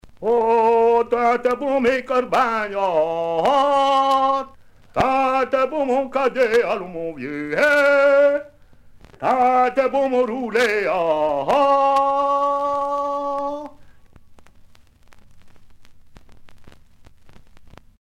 Tiaulage
Curgy
à interpeller, appeler